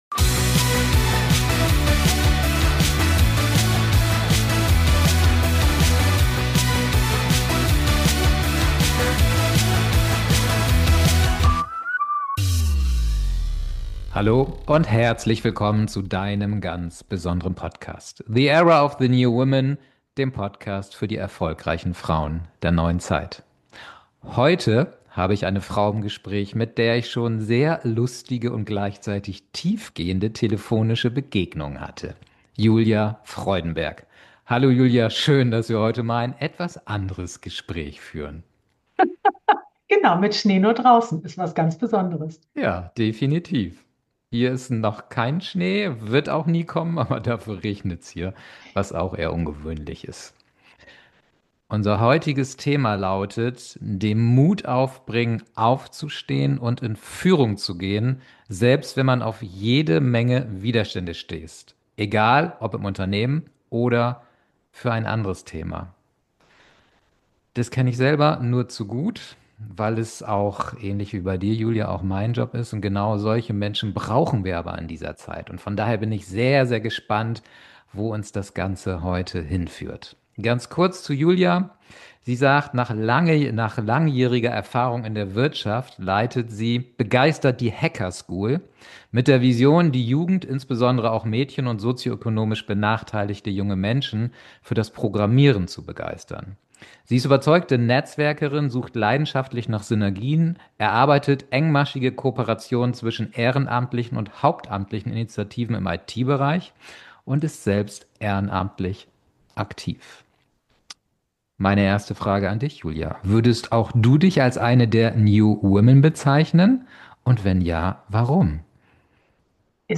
#076 Mut zur Führung – Aufstehen, auch wenn Widerstände kommen. Das Interview